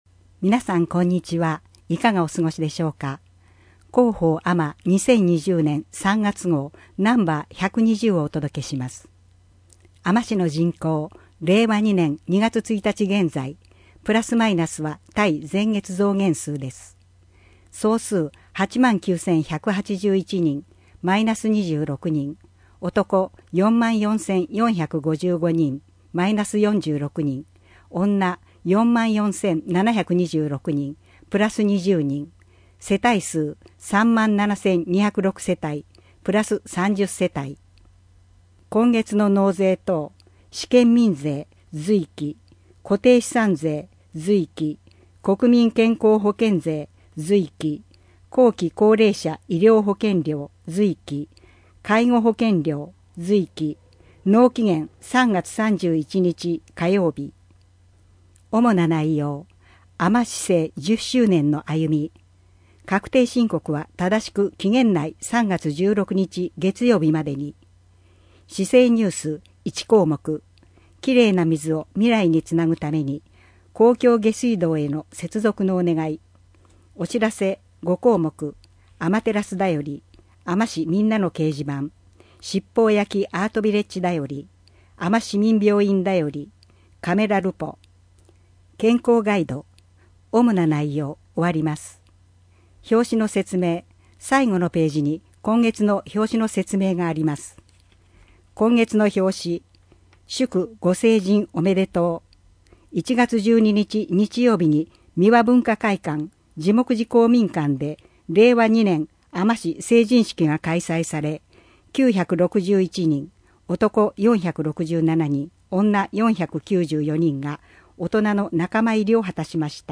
声の広報3月